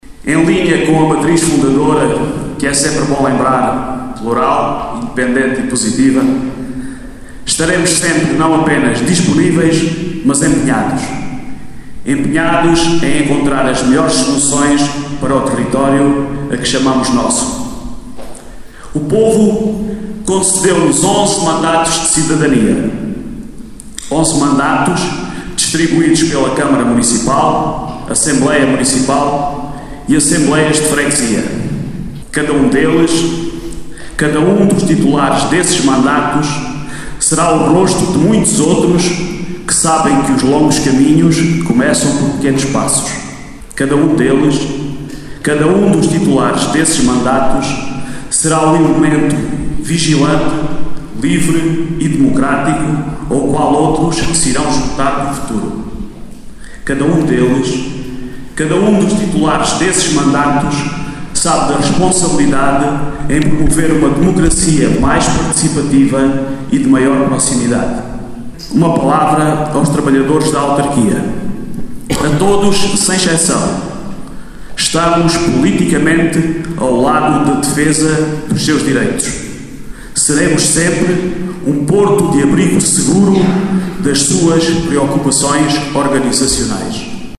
Teve lugar ontem, o Ato de Instalação dos Órgãos do Município de Vidigueira, Assembleia e Câmara Municipal, com a tomada de posse dos novos eleitos para o quadriénio 2021/2025.
Com o salão da Biblioteca Municipal Doutor Palma Caetano repleto, a primeira intervenção esteve a cargo do candidato eleito pelo Mais Cidadãos à Assembleia Municipal, João Roberto, agora deputado municipal, que prometeu “disponibilidade e empenho” em “encontrar as melhores soluções para o território”.